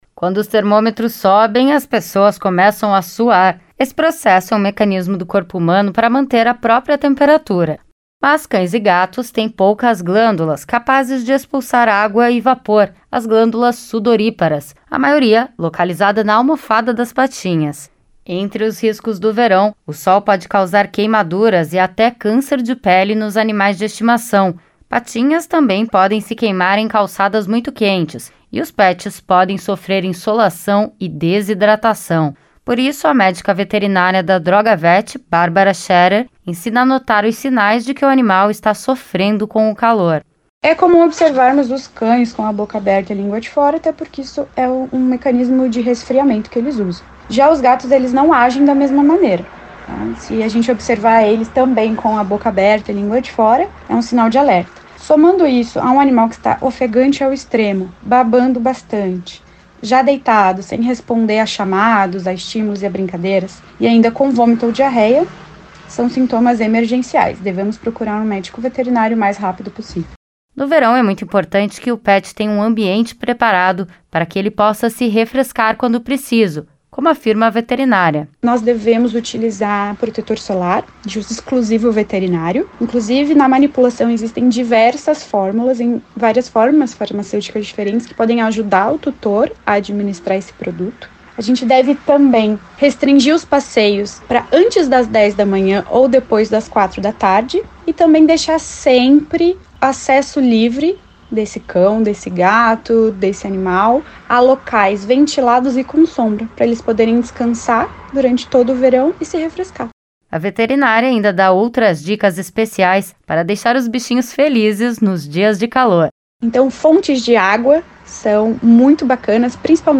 No verão é preciso estar atento para que as altas temperaturas não afetem a saúde dos animais de estimação. A reportagem conta quais os maiores riscos e como proteger a saúde do seu companheiro.